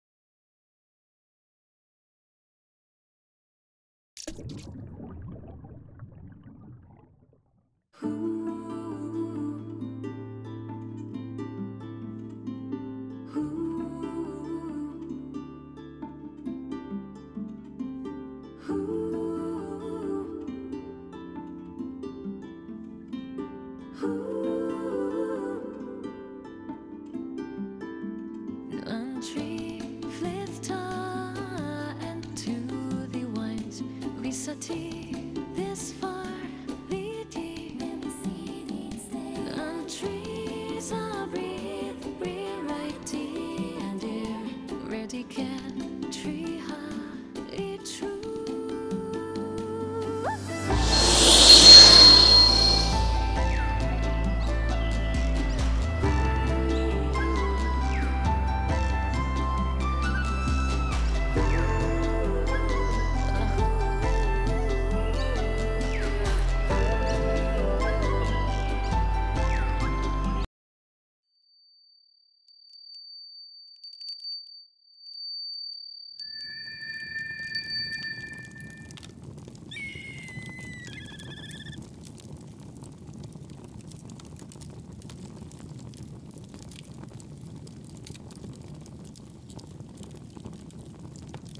【声劇】灰降る晶果。